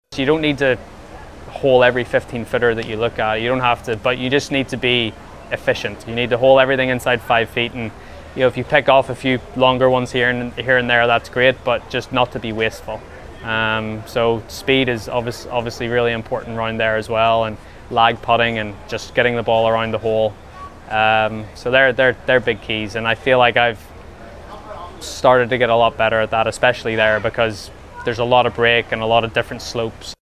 McIlroy’s told the B-B-C that he’s confident about mastering the greens at Augusta – as he looks to compelete the career major grand slam…